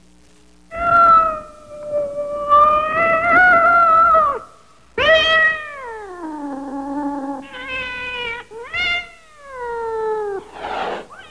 Cat Screech Sound Effect
Download a high-quality cat screech sound effect.
cat-screech.mp3